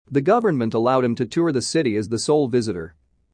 ディクテーション第1問
【ノーマル・スピード】